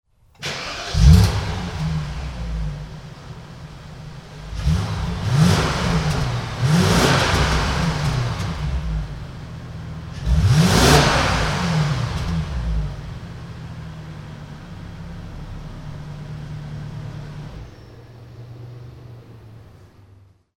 Sons de moteurs bmw - Engine sounds bmw - bruit V8 V10 bmw